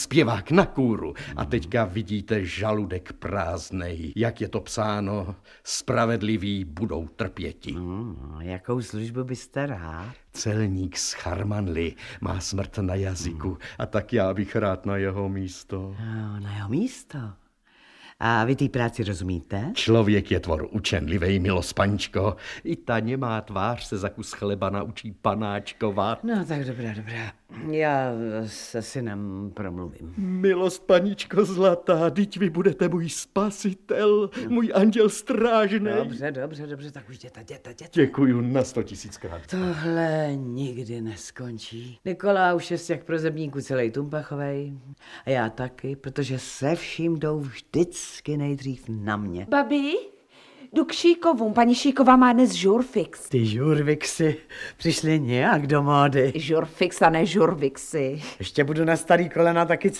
Audiobook
Read: Václav Neužil